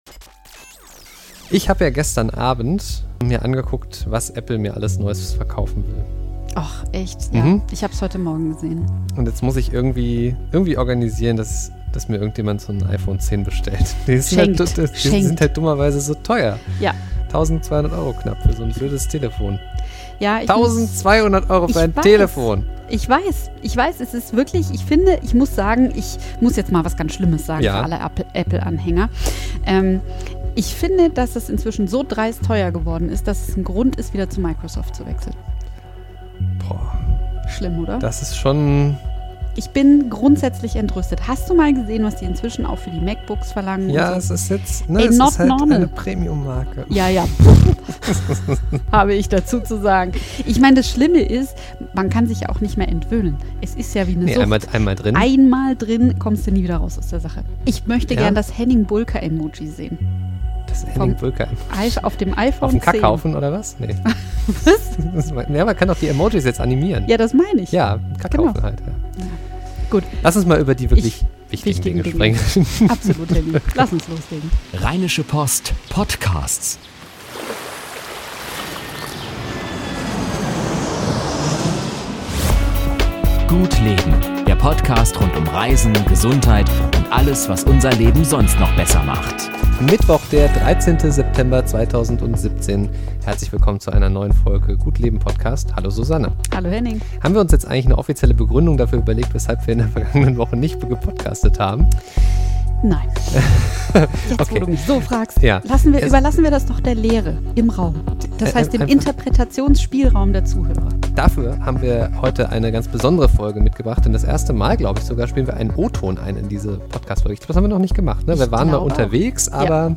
#043 vom 13.09.2017: Interview – So funktioniert die Anti-HIV-Pille für 50 Euro ~ Trip-Tipps für 9 Podcast